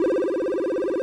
synth14l.wav